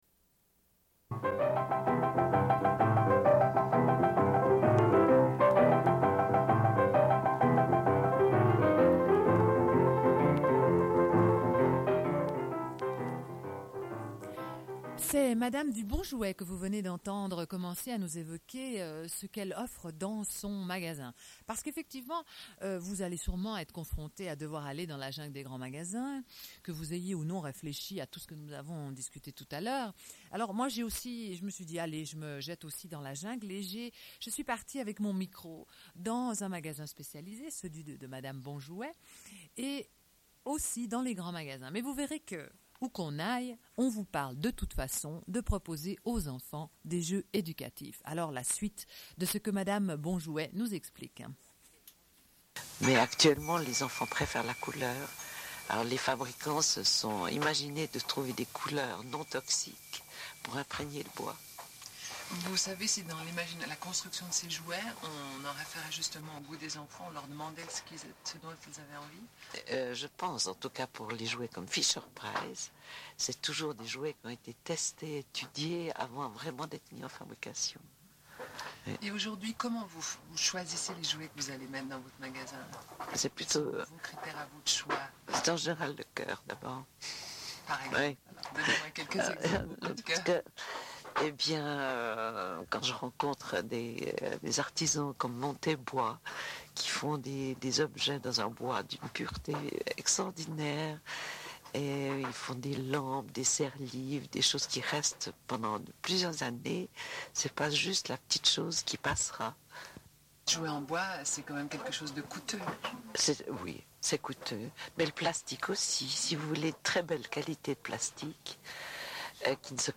Une cassette audio, face B00:47:33
00:00:51 – Entretien avec une personne qui s'occupe d'un magasin de jouet. Diffusion de prises de son dans des magasins de jouets.